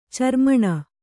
♪ carmaṇa